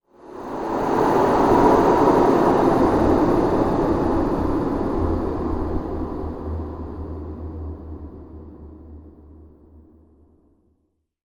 windStart.ogg